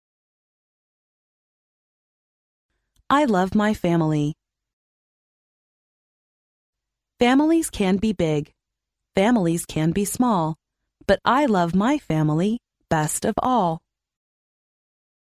幼儿英语童谣朗读 第58期:我爱我的家 听力文件下载—在线英语听力室